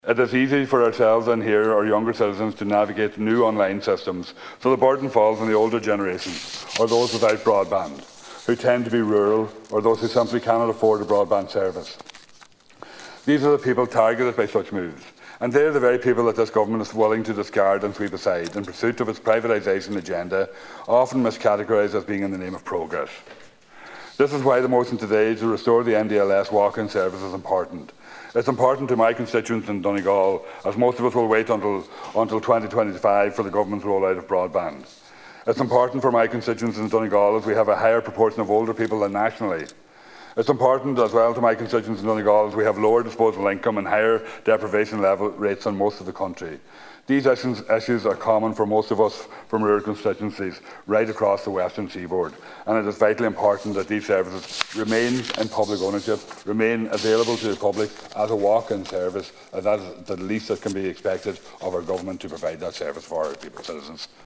That’s according to Donegal Deputy Thomas Pringle who was speaking today on a Private Members’ motion on the National Driver Licence Service.
Deputy Pringle says its a vital service particularly for people in Donegal: